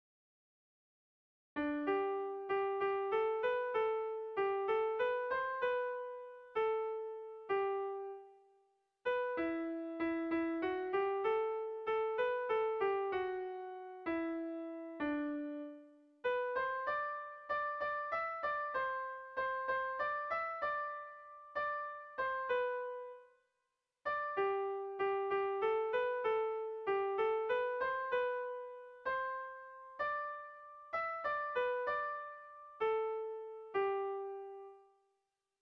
Amodioaren pena II - Bertso melodies - BDB.
A1BDA2